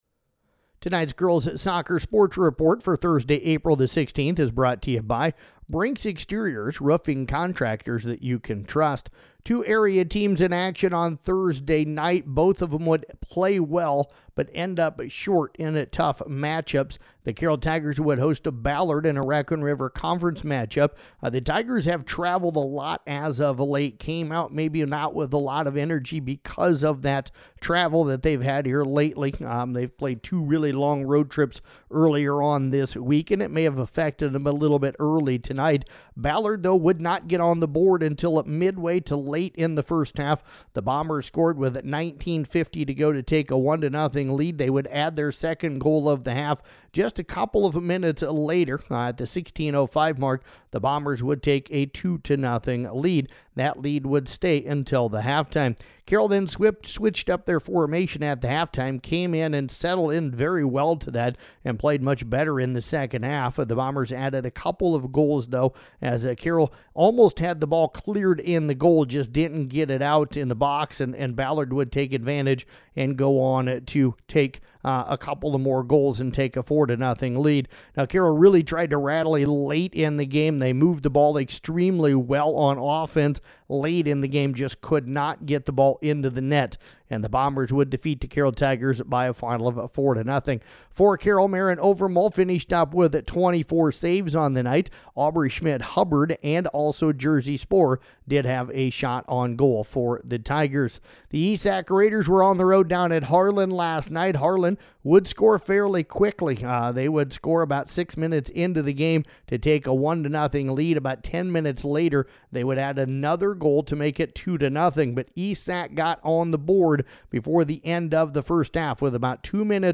Below is an audio recap for Girls Soccer for Thursday, April 16th